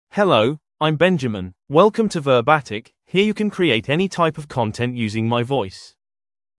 Benjamin — Male English (United Kingdom) AI Voice | TTS, Voice Cloning & Video | Verbatik AI
MaleEnglish (United Kingdom)
Benjamin is a male AI voice for English (United Kingdom).
Voice sample
Benjamin delivers clear pronunciation with authentic United Kingdom English intonation, making your content sound professionally produced.